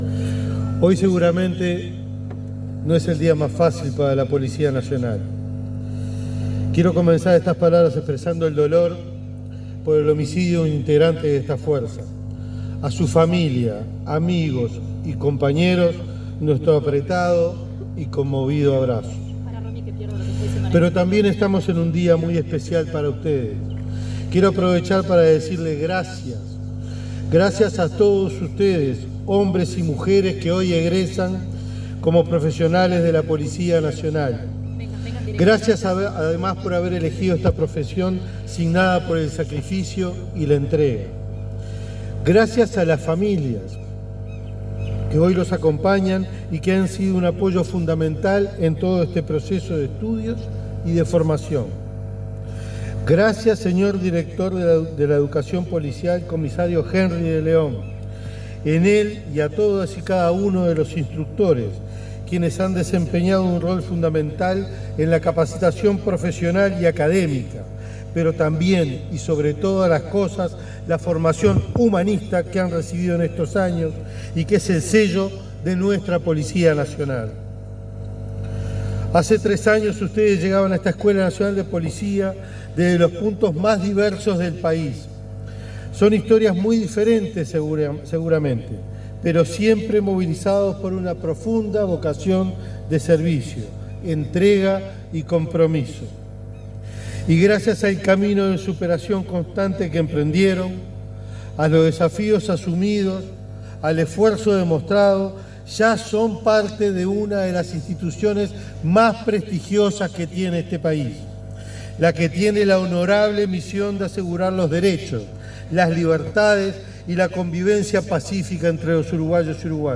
Durante la ceremonia de egreso de la Escuela Nacional de Policía, el ministro del Interior, Carlos Negro, hizo uso de la palabra.